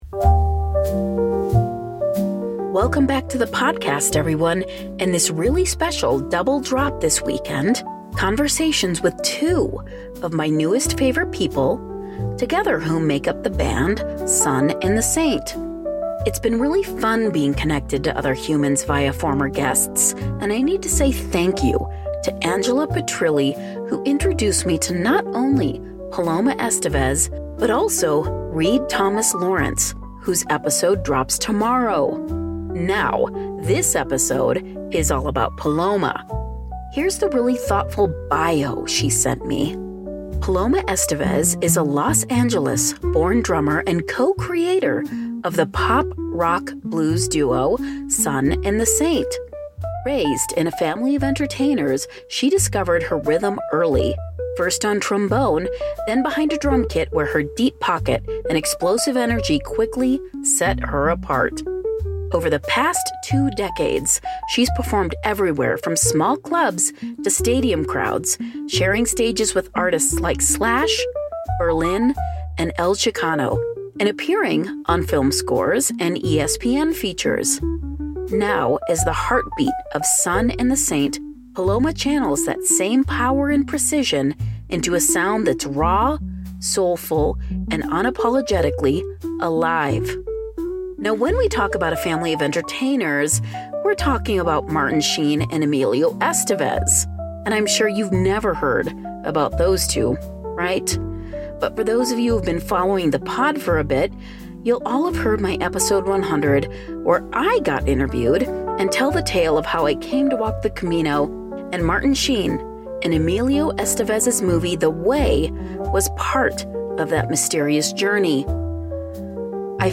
Our conversation was truly delightful, and hearing her stories helped me understand that it is only through hard work, and deep dreaming, that we all can attain our desires!